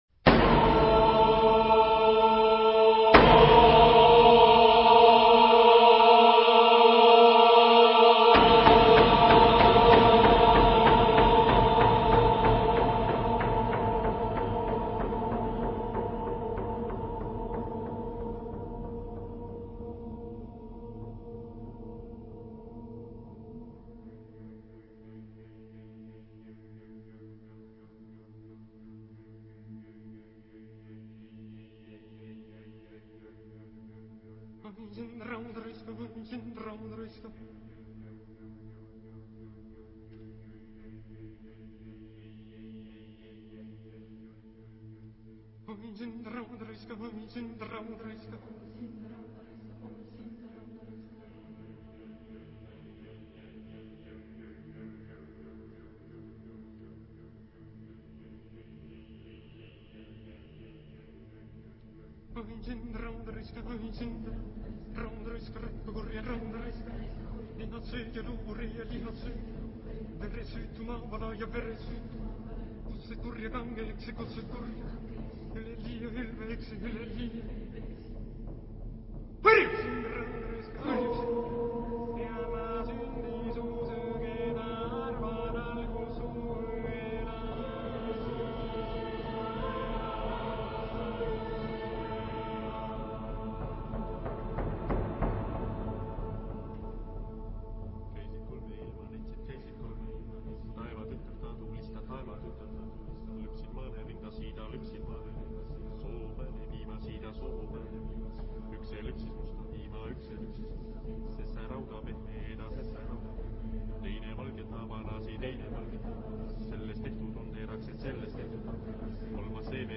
Tipo de formación coral: SATB  (4 voces Coro mixto )